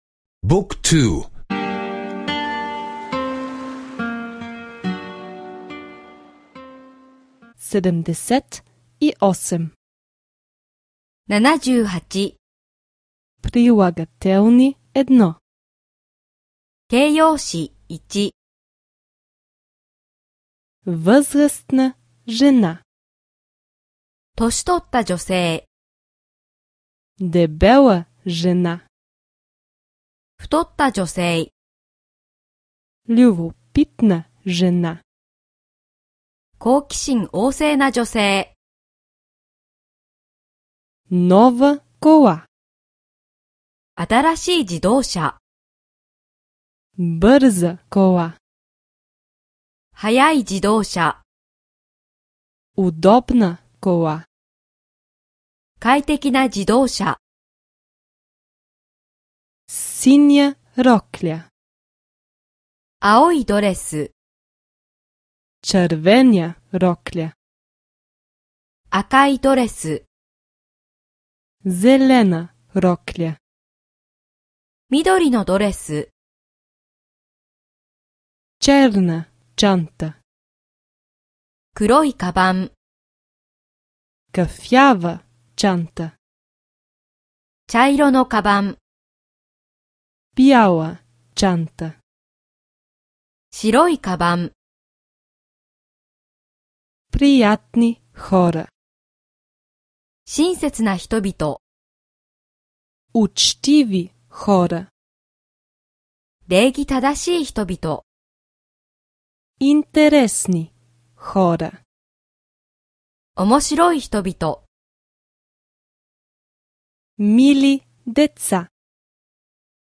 Аудиокурс по японски език (безплатно сваляне)